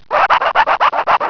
A weird scratching noise